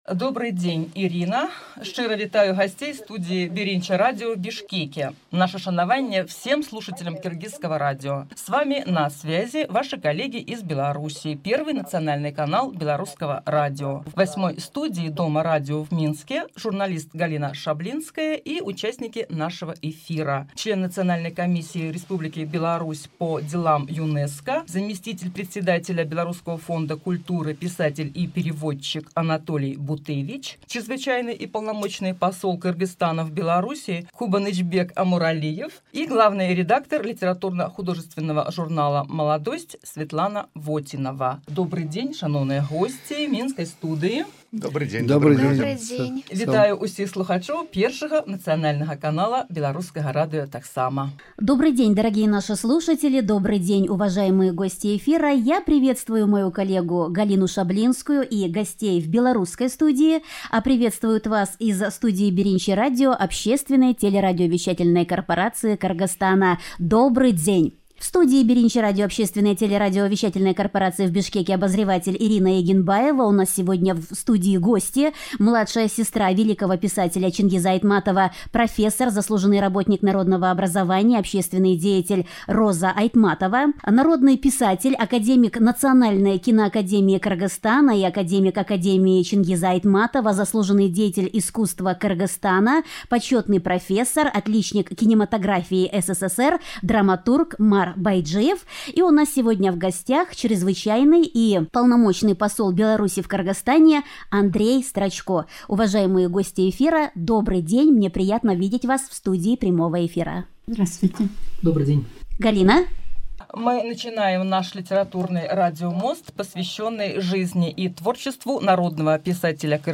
Радиомост «Минск-Бишкек», посвящённый творчеству советского писателя Чингиза Айтматова, в эфире Первого канала Белорусского радио | Радио Беларусь